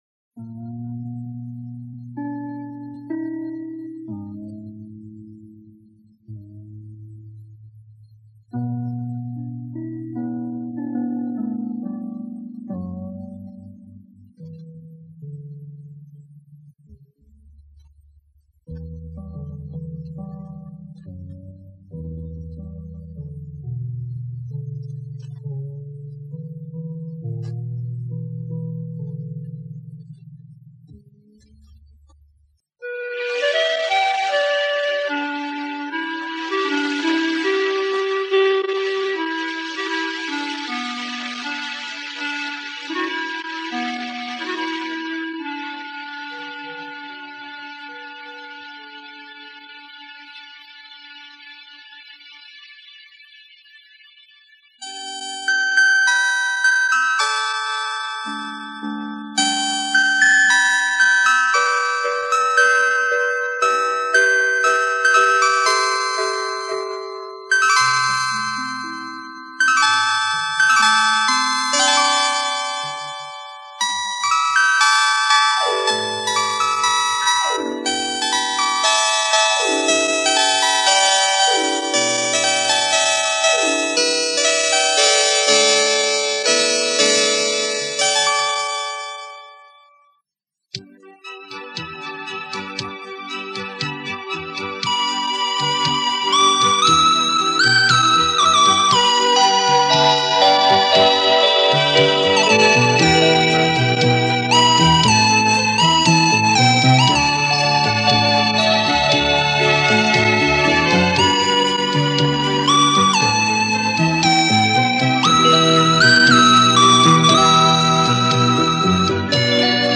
卡带的声音都有 唉~~